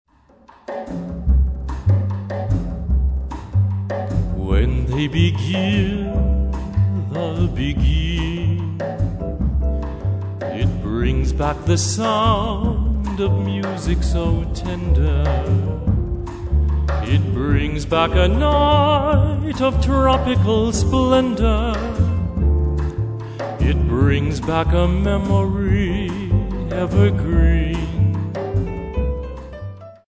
Vocals
Piano
Bass
Drums